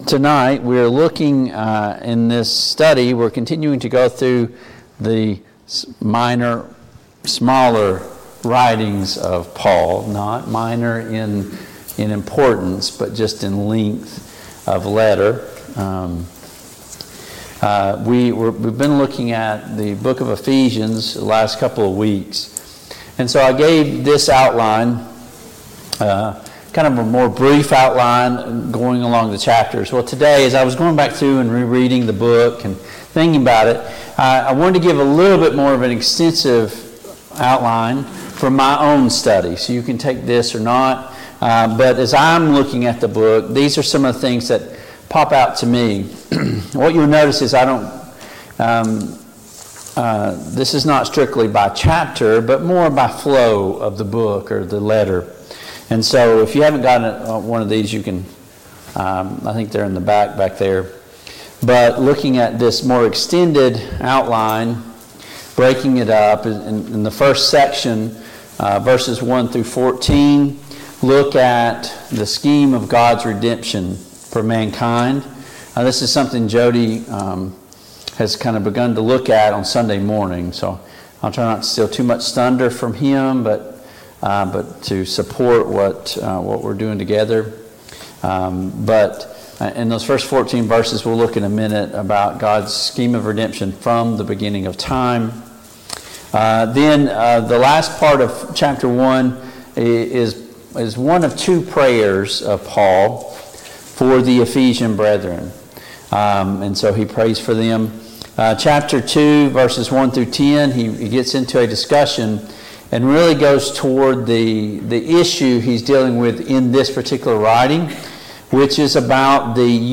Passage: Ephesians 1, Ephesians 2 Service Type: Mid-Week Bible Study